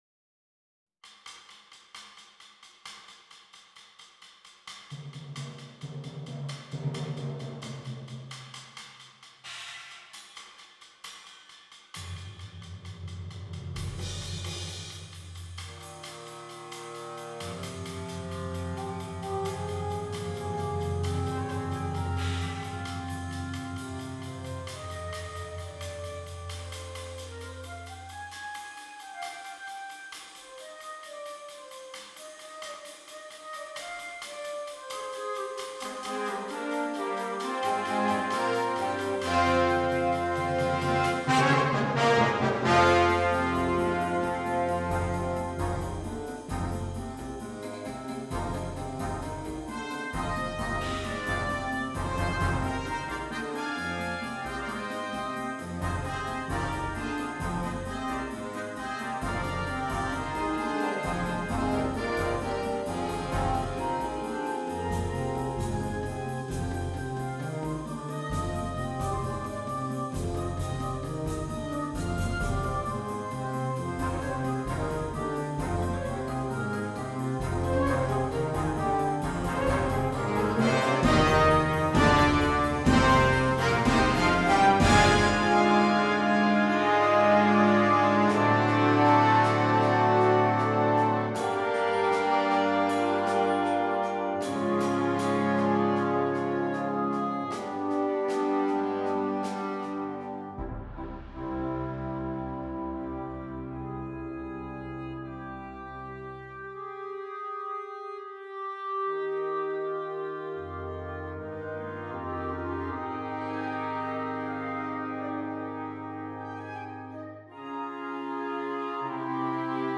Gattung: Konzertwerk für Symphonic Band
Besetzung: Blasorchester